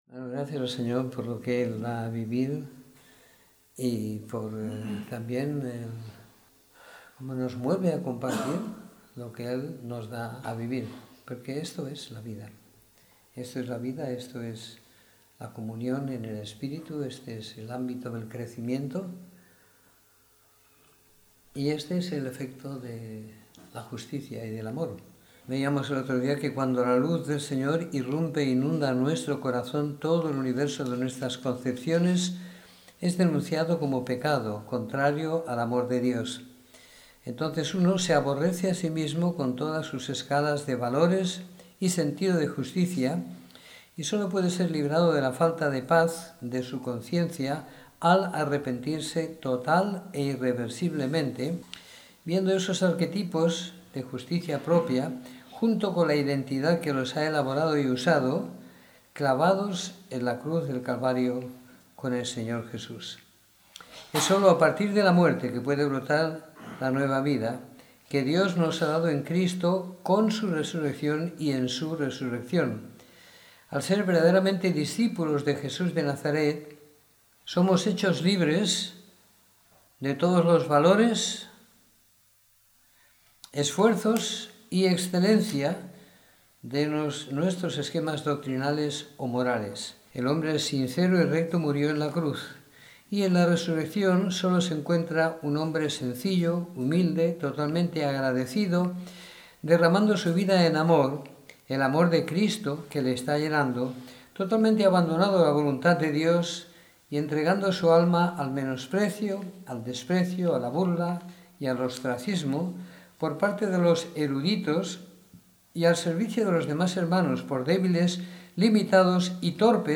Domingo por la Tarde . 11 de Diciembre de 2016